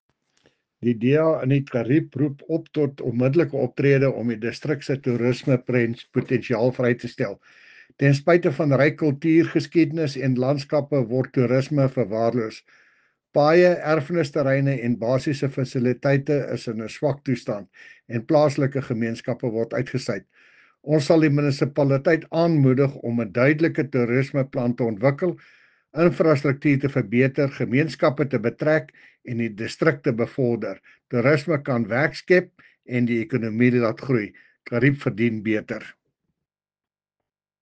Afrikaans soundbites by Cllr Jacques van Rensburg and Sesotho soundbite by Jafta Mokoena MPL.